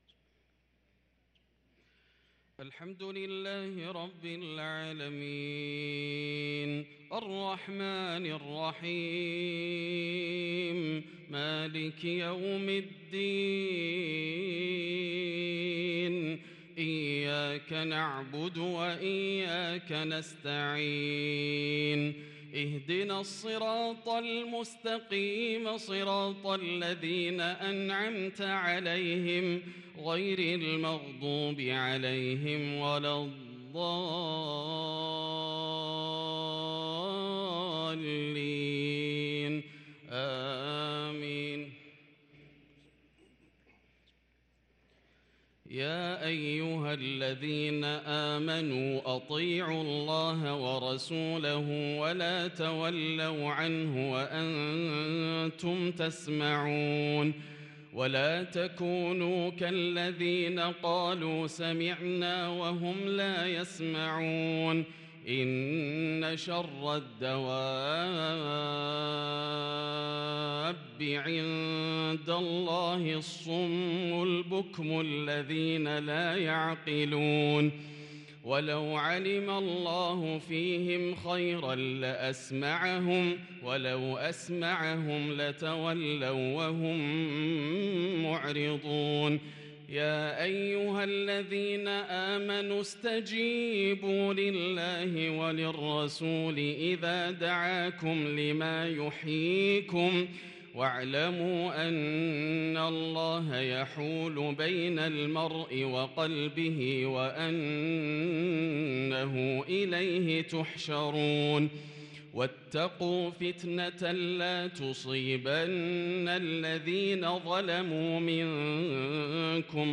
صلاة العشاء للقارئ ياسر الدوسري 19 جمادي الأول 1444 هـ
تِلَاوَات الْحَرَمَيْن .